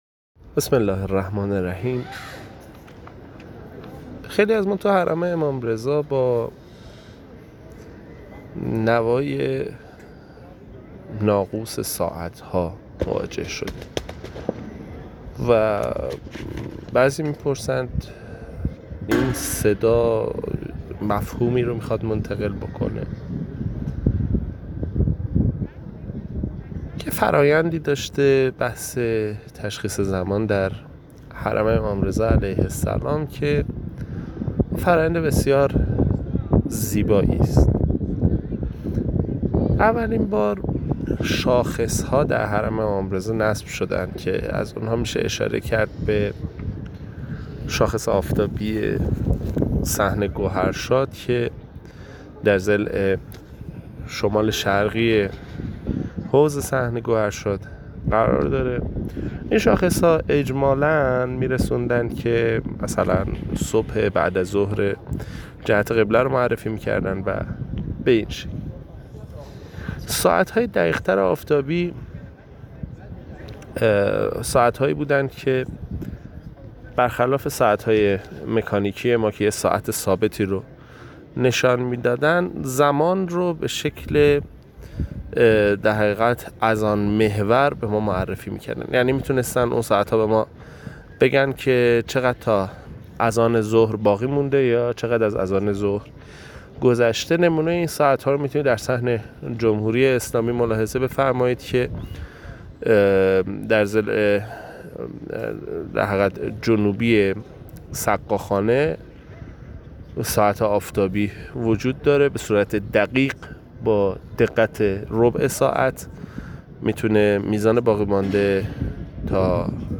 ساعت حرم
ساعت‌های حرم رضوی در صحن انقلاب و آزادی صداهای گوش‌نوازی دارند که در خاطر بسیاری از زائران ماندگار شده است.